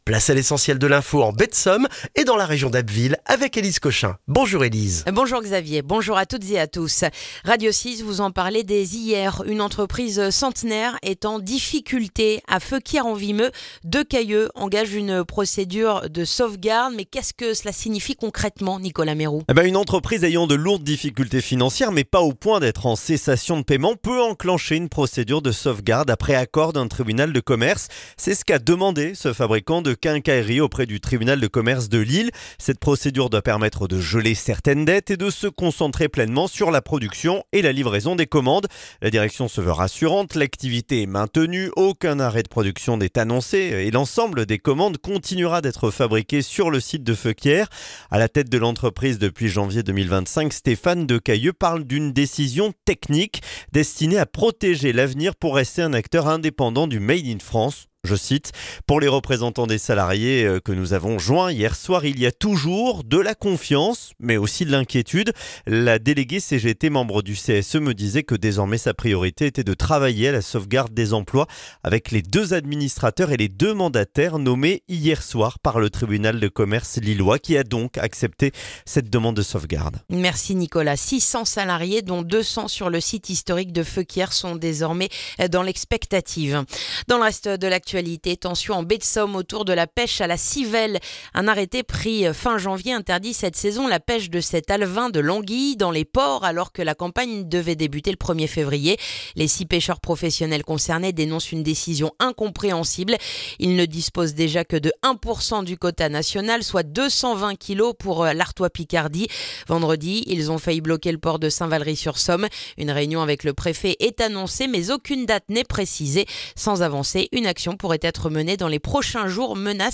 Le journal du mardi 10 février en Baie de Somme et dans la région d'Abbeville